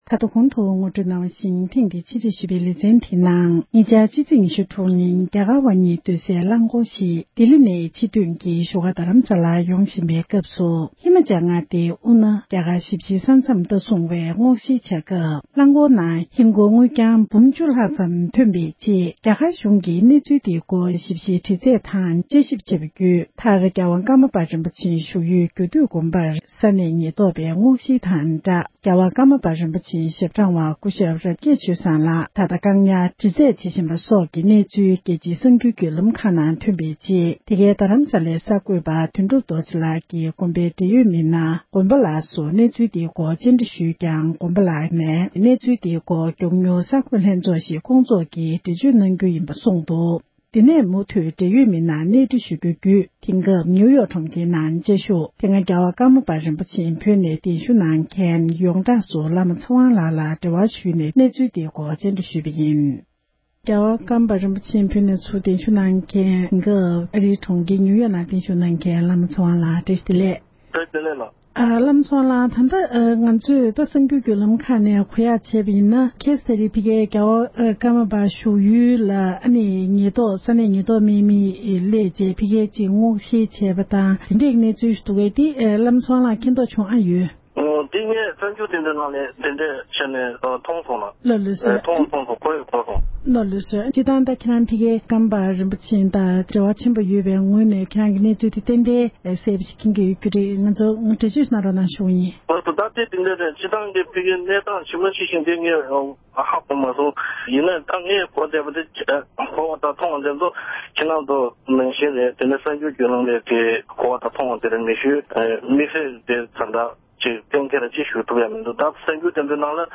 འབྲེལ་ཡོད་མི་སྣར་བཀའ་འདྲི་ཞུས་པ་ཞིག་གསན་རོགས༎